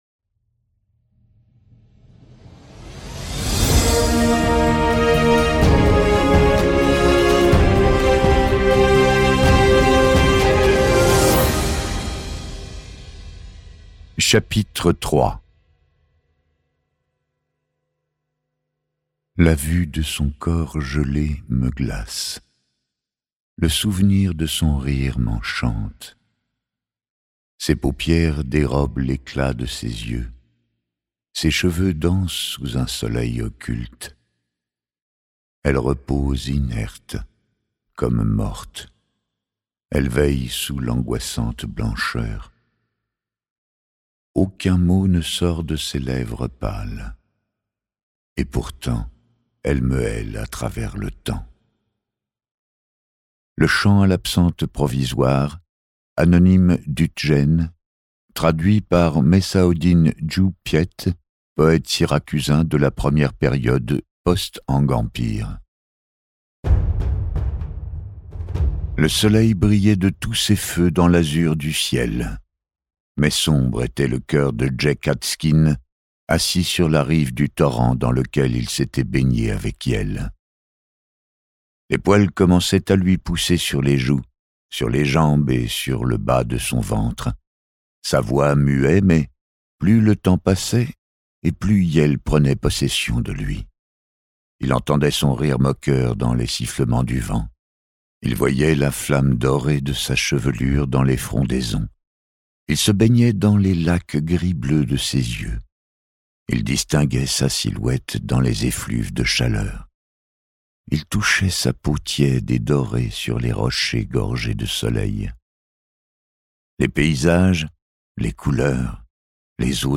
Pendant ce temps, Tixu Oty, arrivé sur un astre mort du centre de la galaxie, pénètre dans les arcanes de l'Hyponéros...Ce livre audio est interprété par une voix humaine, dans le respect des engagements d'Hardigan.